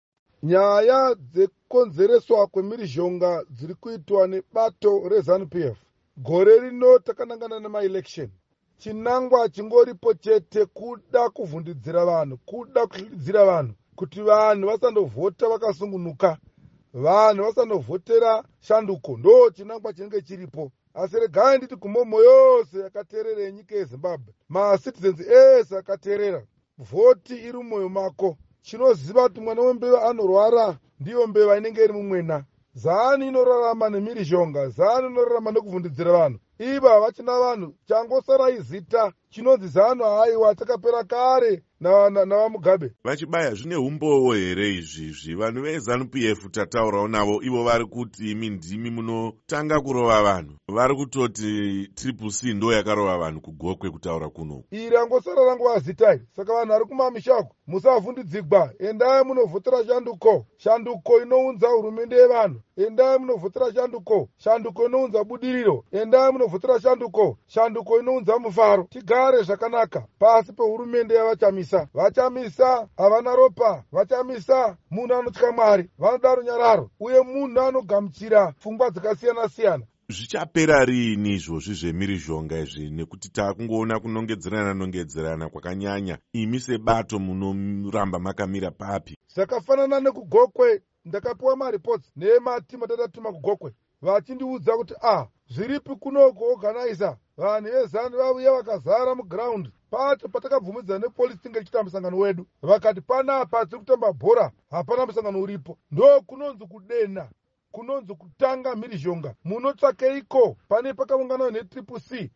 Hurukuro na Va Amos Chibaya